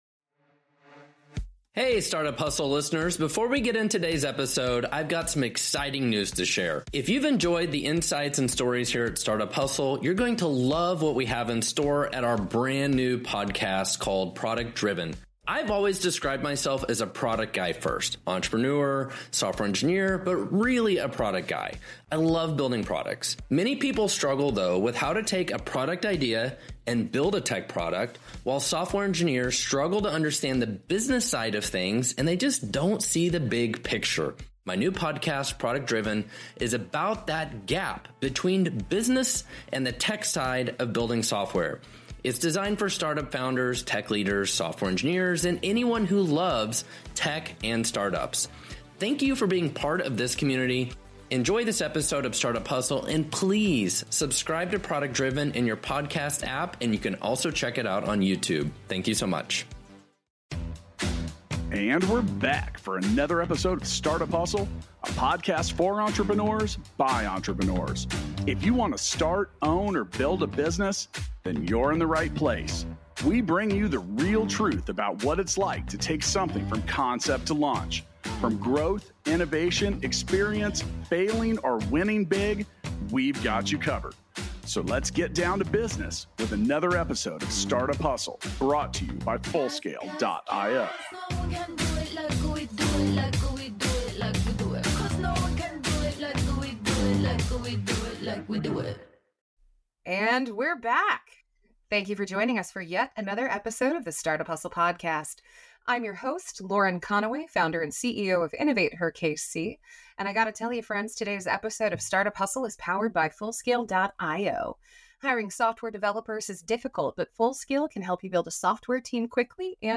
for a discussion about unlocking capital opportunities.